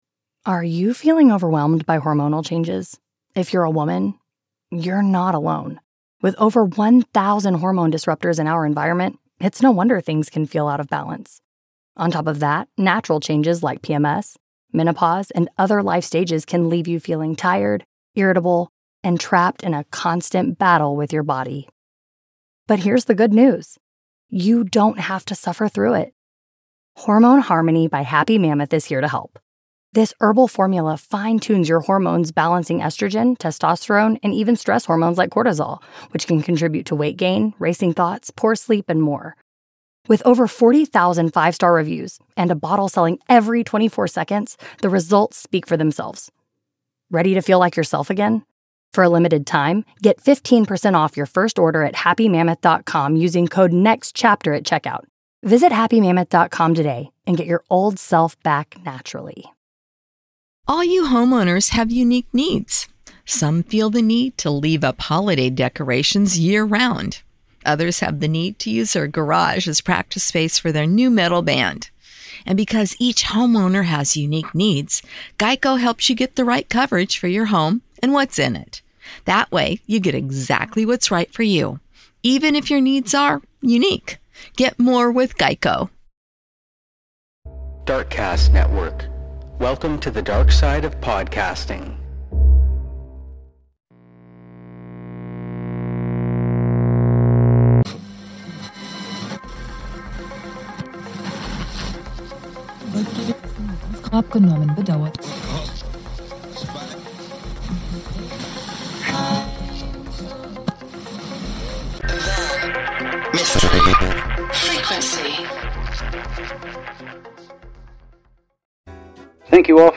Old Time Radio Show | Applause | Classic Mixed Bag Audio Comedy. Comedy - Variety.